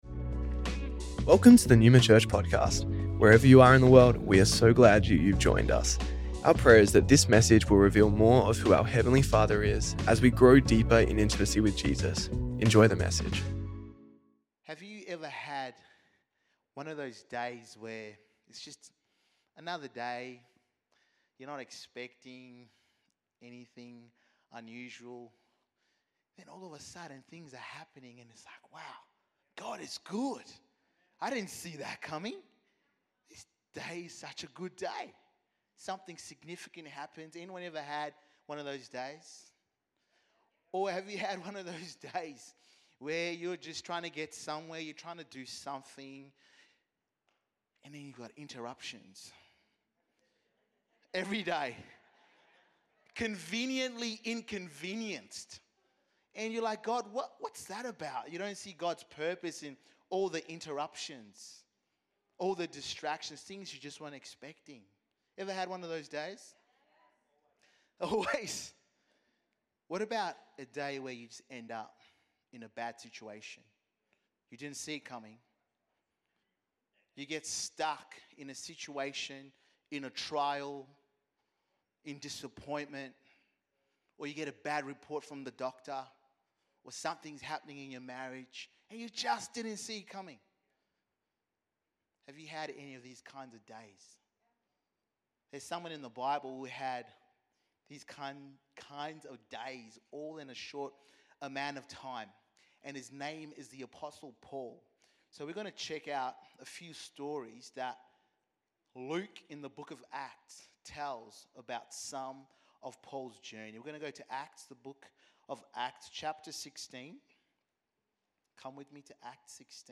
Neuma Church Melbourne South Originally recorded at the 10AM Service on Friday 18th April 2025